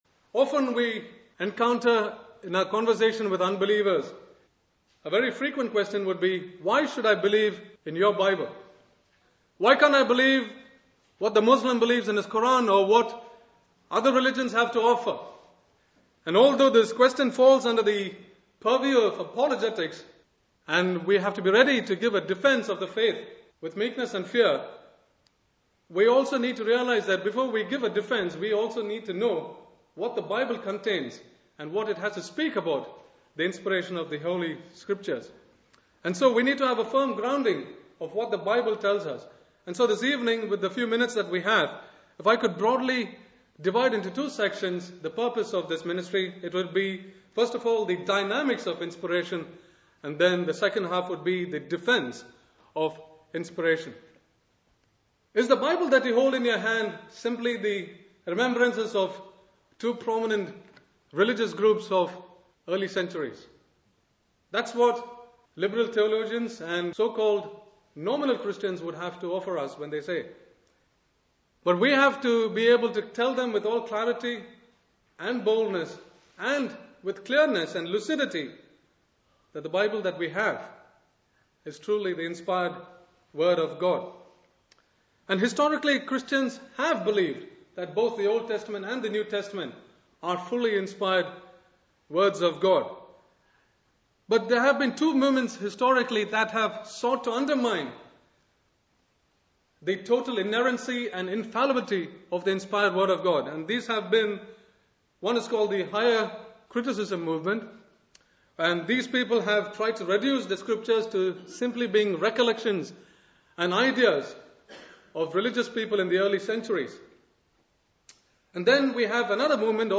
teaches on the subject of the inspiration of scripture. This is a most important, vital and fundamental subject on which every believer must have an intelligent grasp (Message preached 21st June 2007)